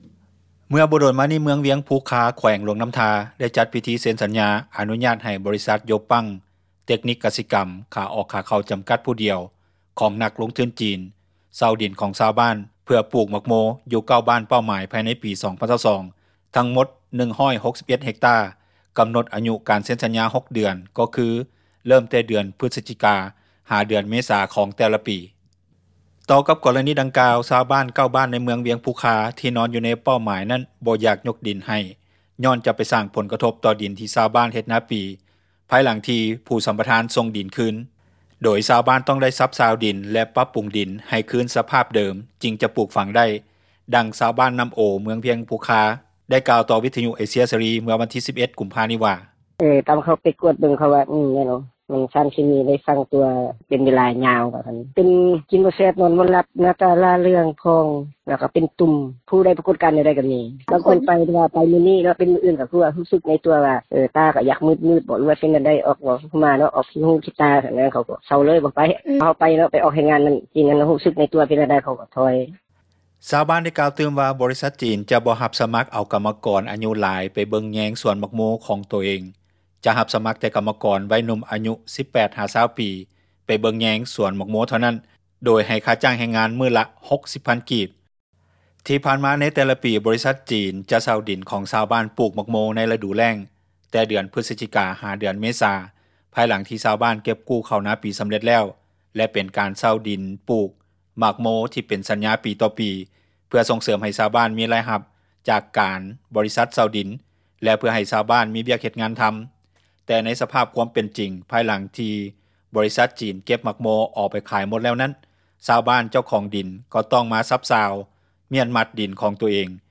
ນັກຂ່າວ ພົລເມືອງ